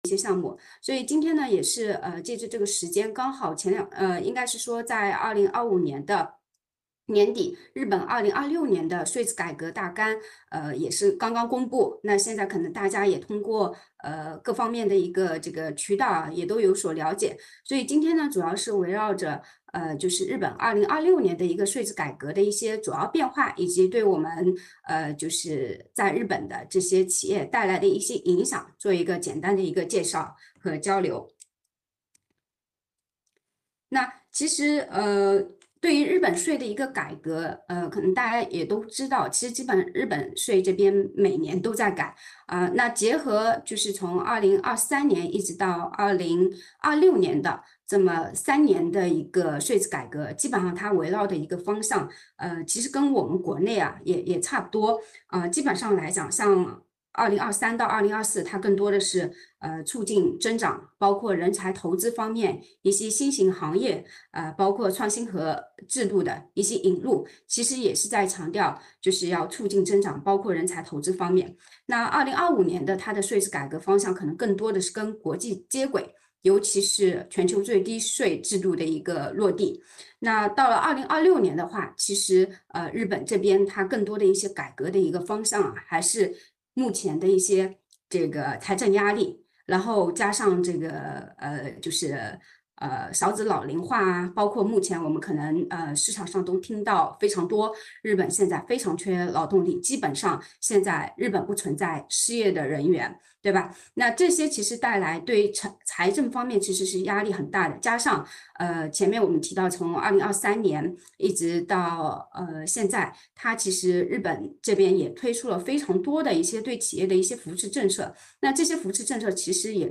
视频会议